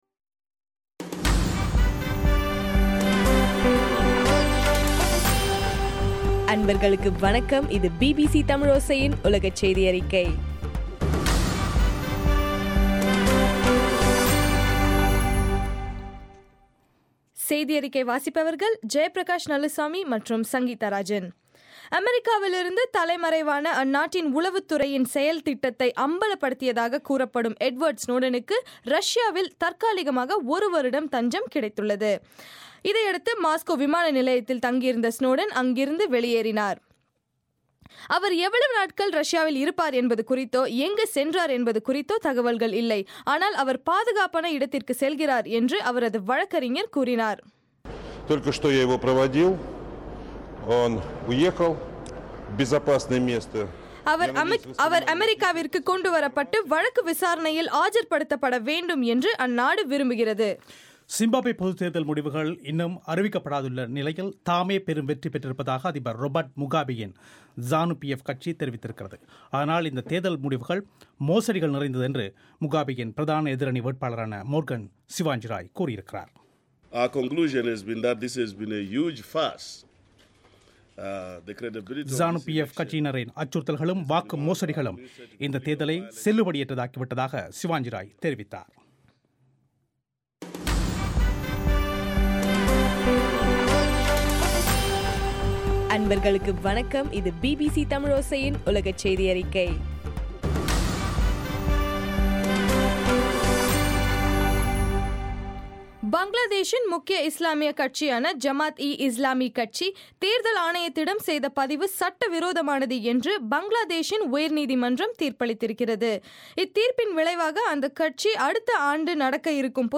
ஆகஸ்ட் 1 தமிழோசையின் உலகச் செய்திகள்